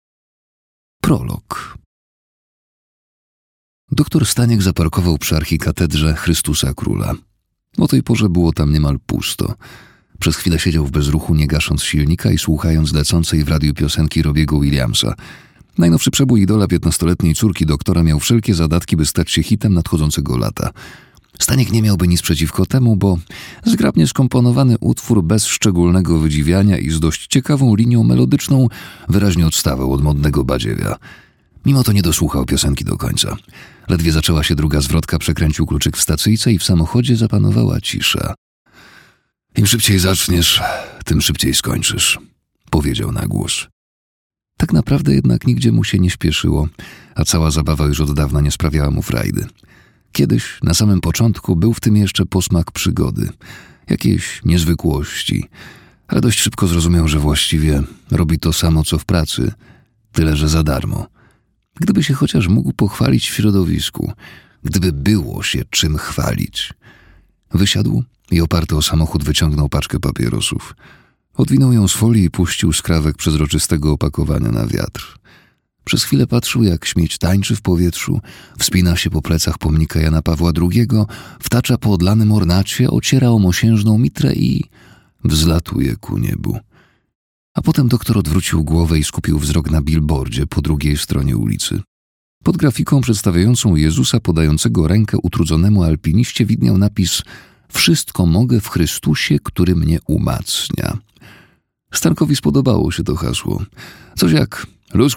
Kup audiobook: Ciemność płonie.